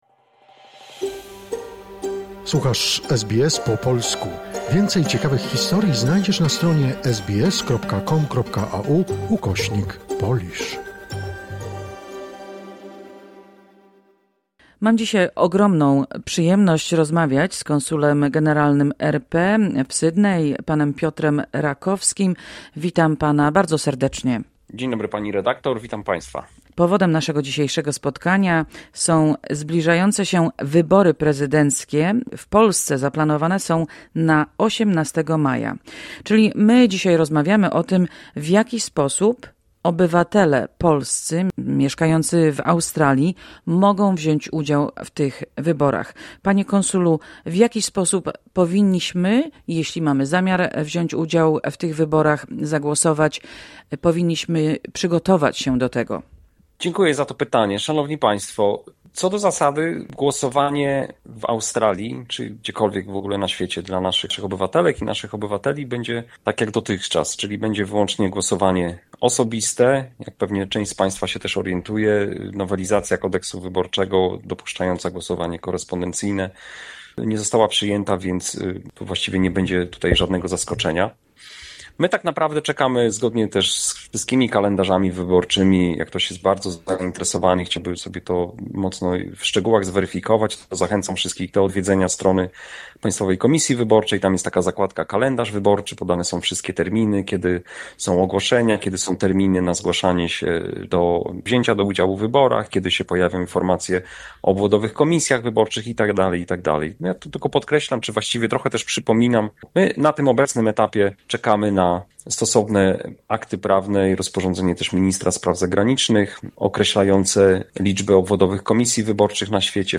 Spotkanie z Konsulem Generalnym RP w Sydney Panem Piotrem Rakowskim. Rozmawiamy o zbliżajacych się polskich wyborach prezydenckich, w których mogą wziąć udział w Australii polscy obywatele.